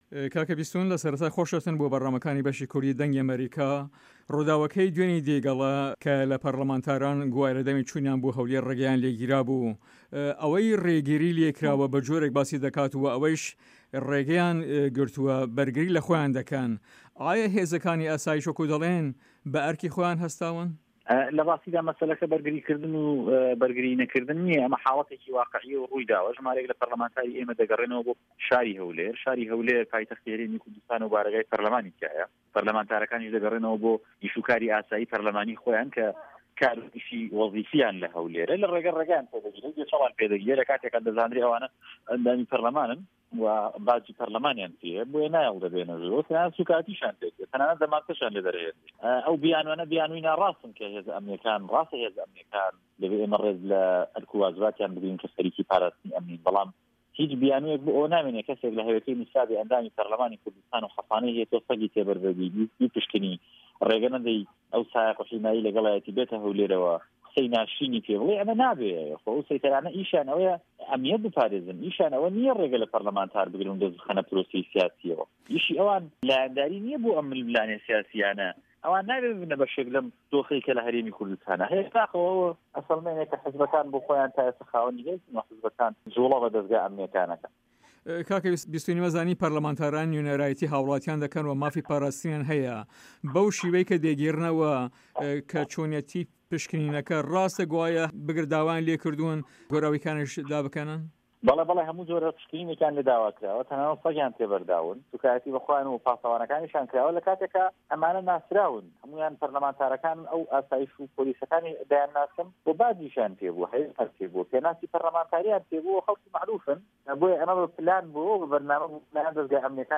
بیستون فایق ئەندام پەرلەمانی هەرێمی کوردستان لە سەر لیستی بزوتنەوەی گۆڕان لە هەڤپەیڤینێکدا لەگەڵ بەشی کوردی دەنگی ئەمەریکا دەڵێت"لە راستیدا کێشەکە بەرگری کردن و بە رگری نەکردن نییە، ئەمە شتێکە رووی داوە ،کە ژمارەیەک لە پەرلەمانتارانی ئێمە دەگەرێنەوە شاری هەولێربۆ کاری ئاسایی خۆیان لە پەرلەمانی هەرێمی کوردستان بەڵام لە رێگادا هەرچەند ئەوانە دەزانن ئەمانە ئەندام پەرلەمانن و باجی پەرلەمانیان پێیە بەڵام رێگایان لێ دەگرن و ناهێڵن بیێنە ژوور و سوکایەتیشیان پێی دەکەن و تەنانەت دەمانچەشیان لێ را دەردەهێنن.